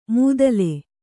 ♪ mūdale